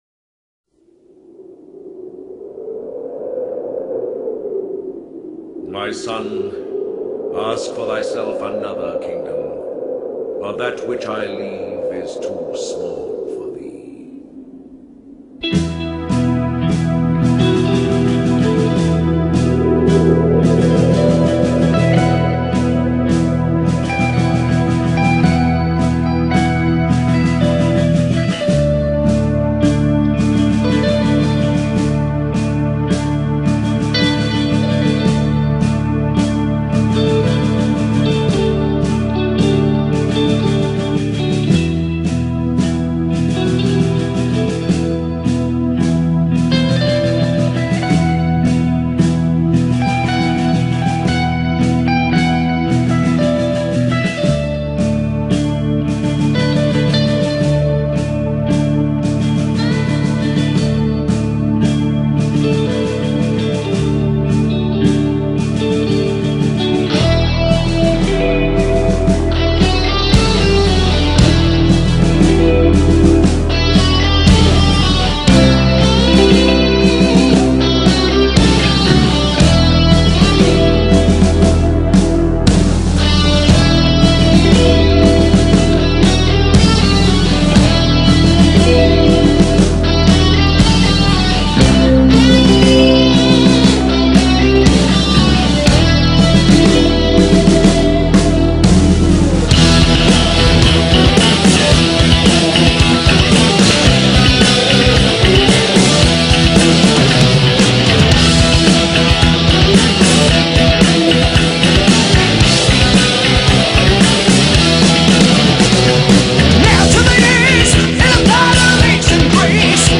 Une énigme musicale pour cette première quinzaine de juin.